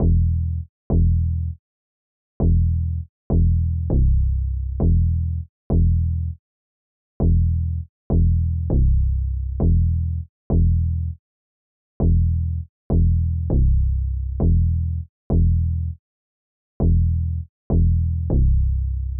RIP Nipsey Hussle Type Bassline West Coast Players
Tag: 100 bpm Rap Loops Bass Synth Loops 3.23 MB wav Key : Unknown FL Studio